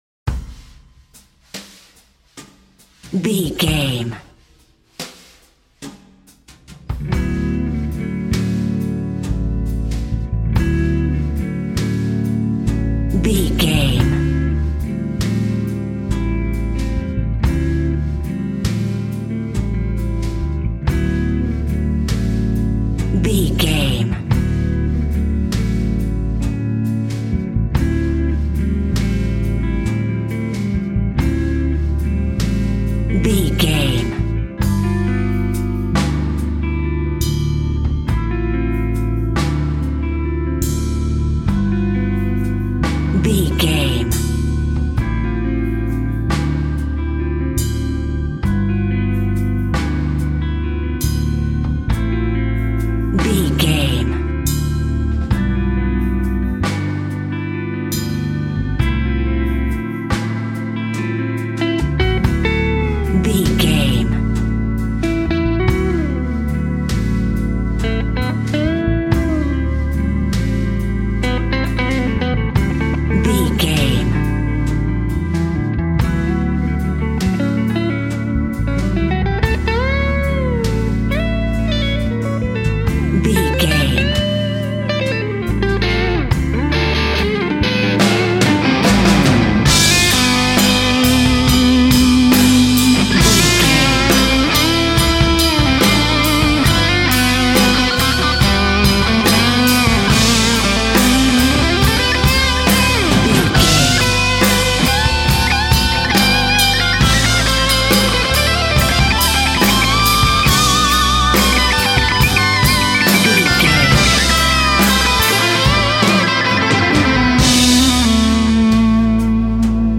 peaceful, content, dreamy, romantic, serene, soothing
Aeolian/Minor
Slow
drums
synthesiser
electric guitar